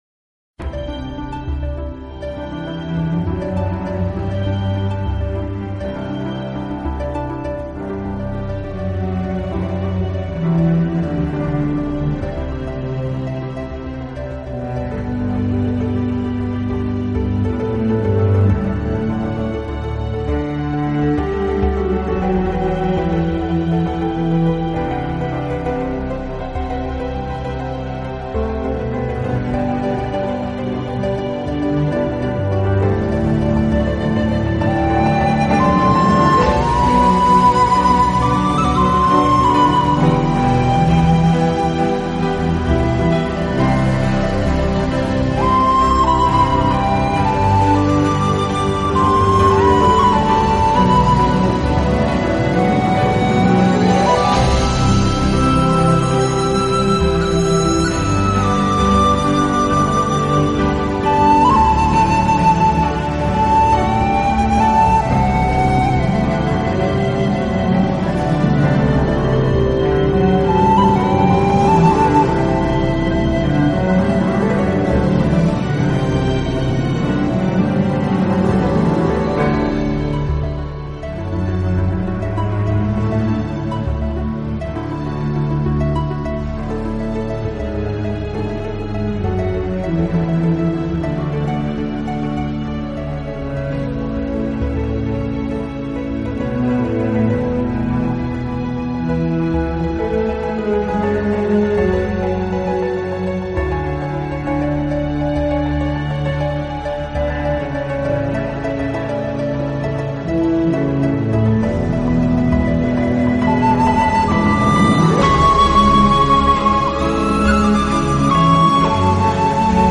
令人抚慰的音乐和惬意的旋律。
音乐旋律优美独特，琴声清脆明亮。